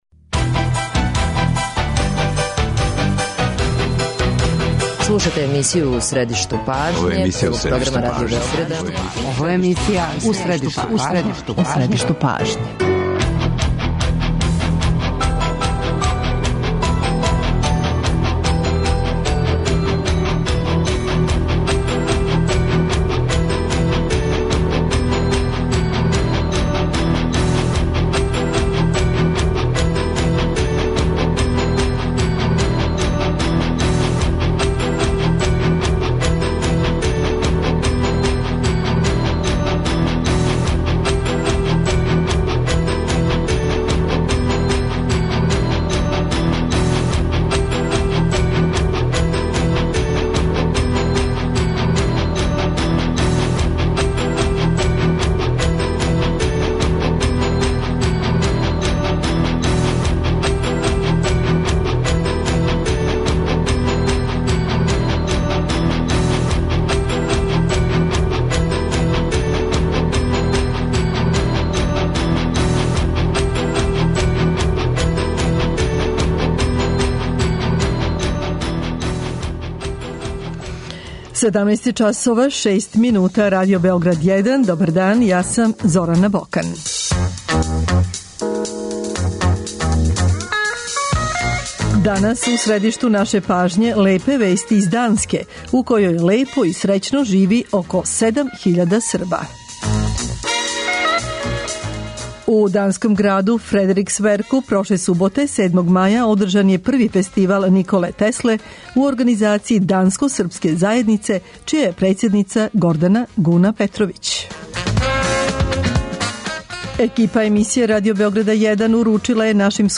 Екипа Радио Београда боравила је у данском граду Фредериксверку у коме је откривена биста Николе Тесле.
Говоре грађани и челници Фредериксверка.